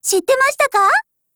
贡献 ） 协议：Copyright，其他分类： 分类:少女前线:MP5 、 分类:语音 您不可以覆盖此文件。